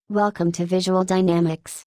welcome-message.wav